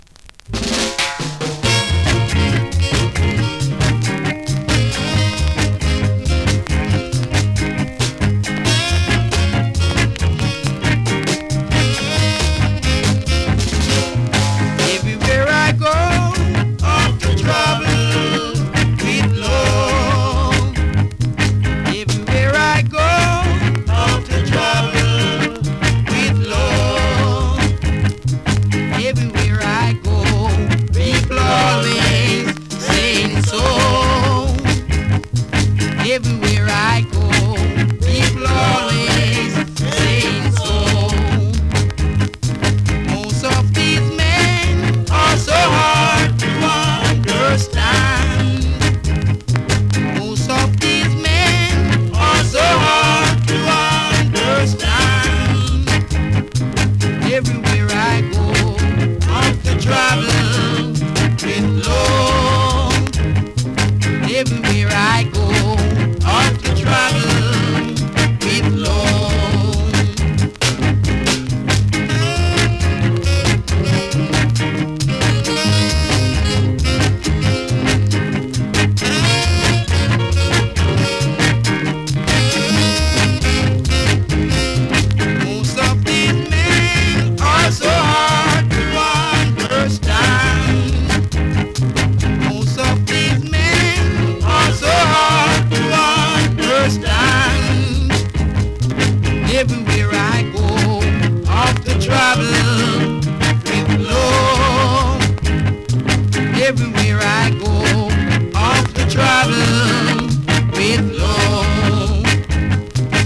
スリキズ、ノイズそこそこありますが